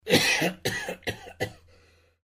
cough2.mp3